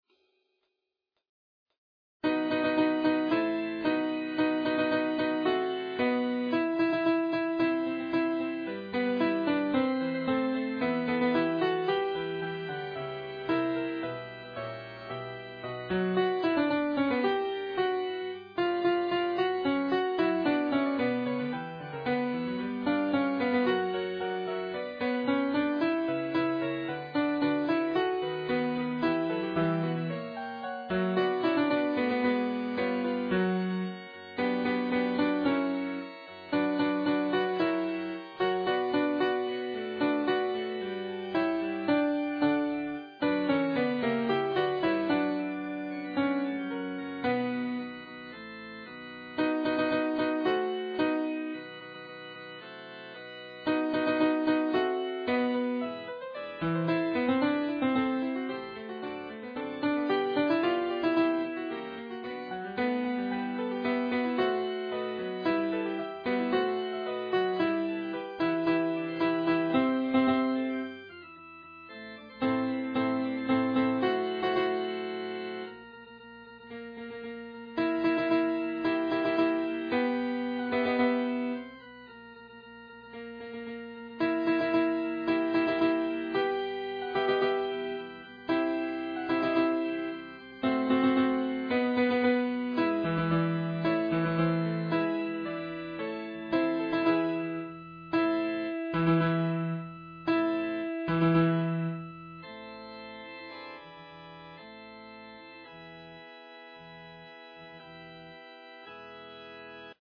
Les fichiers de travail mettent en relief au piano chacune des voix dans le contexte général ; ce sont des fichiers .mp3 qui peuvent être lus par un ordinateur, un lecteur mp3, ou directement gravés sur un CD audio.
Lorsque le choix en a été possible, le tempo des fichiers de travail est bien entendu un tempo de travail, et ne reflète pas nécessairement celui de notre interprétation finale...